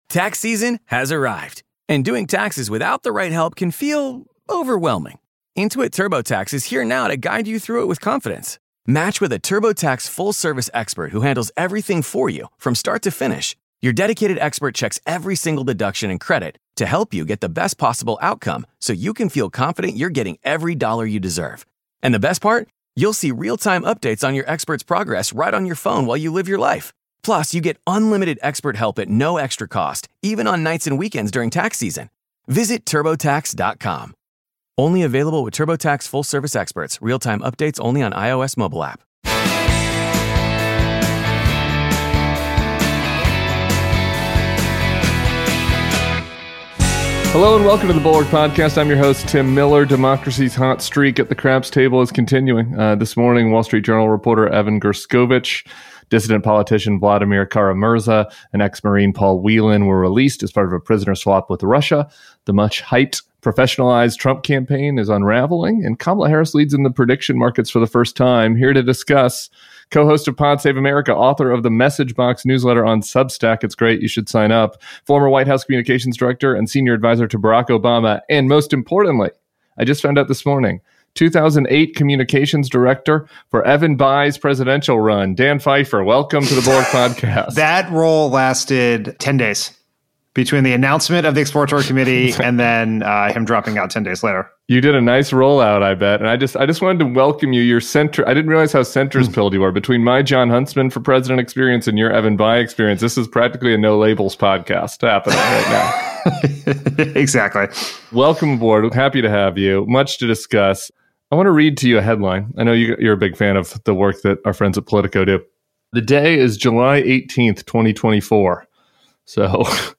At the same time, the election has quickly shifted from a referendum on Joe Biden to one about the future vs. the past. Dan Pfeiffer joins Tim Miller today. show notes: Dan's Message Box newsletter